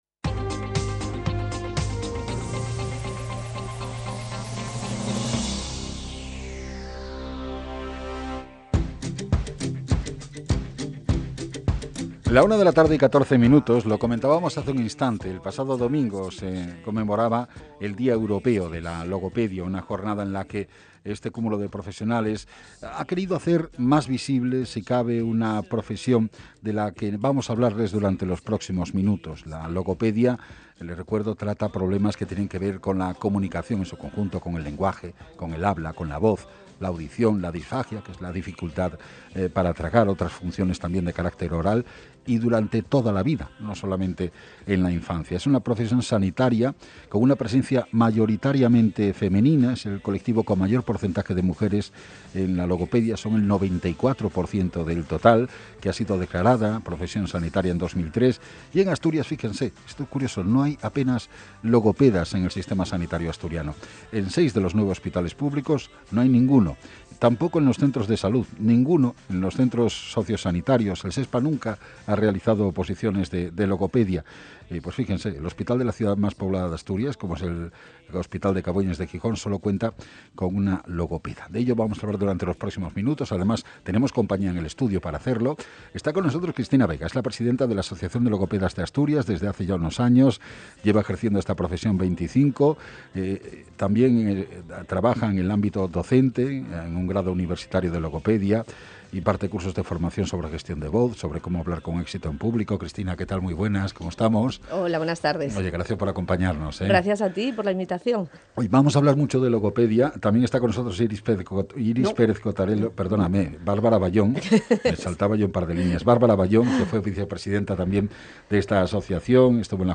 Entrevista en Onda Cero